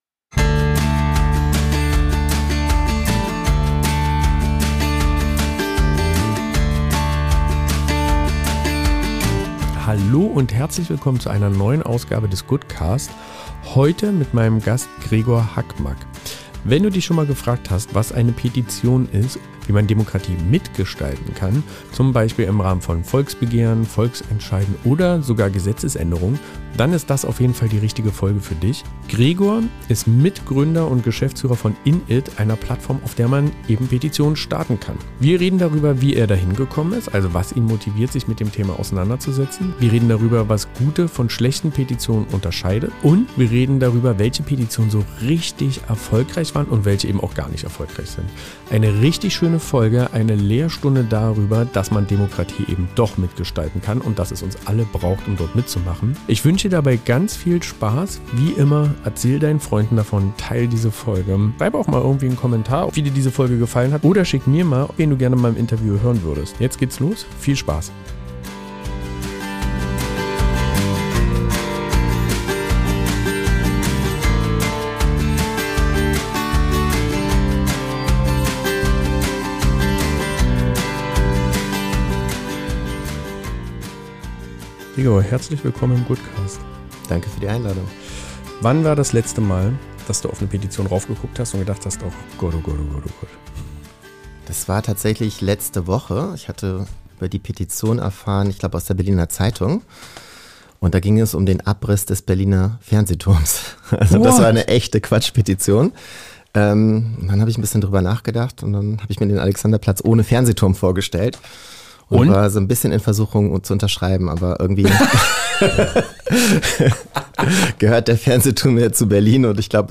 Ein warmes, inspirierendes Gespräch über Verantwortung, Engagement und die vielen kleinen Schritte, mit denen große Veränderungen beginnen.